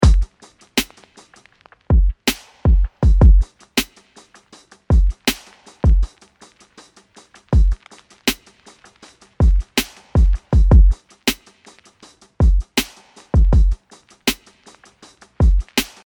LoFi Beat